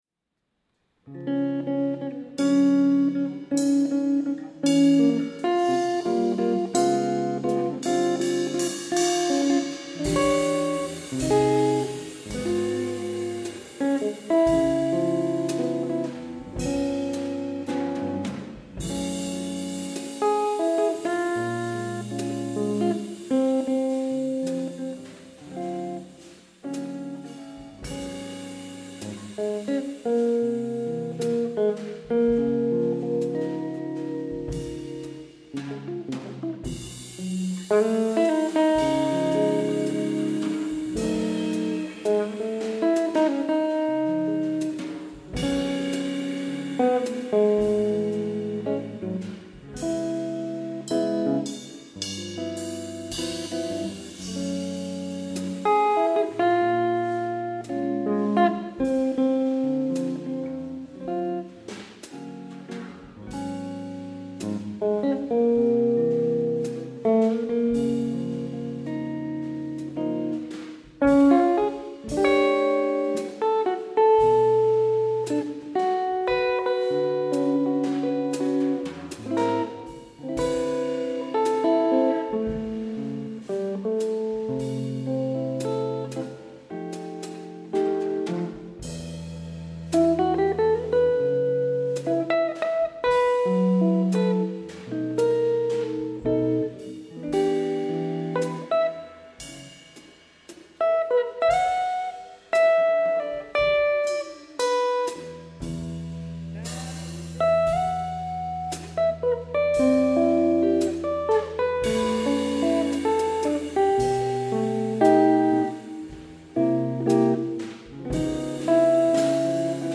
This is a live recording
drums